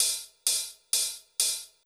TEC Beat - Mix 18.wav